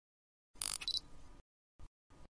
Tiếng Lấy nét, Focusing của Camera DSLR, Máy ảnh cơ
Tiếng Bấm camera, Lấy nét, chụp ảnh… Tiếng Lấy nét, Focus, Click… Camera máy ảnh
Thể loại: Tiếng đồ công nghệ
Âm thanh này thường được mô phỏng từ máy ảnh DSLR, máy ảnh cơ, máy ảnh truyền thống, với tiếng “rè rè”, “click” hay tiếng xoay nhẹ của ống kính lấy nét.
tieng-lay-net-focusing-cua-camera-dslr-may-anh-co-dslr-www_tiengdong_com.mp3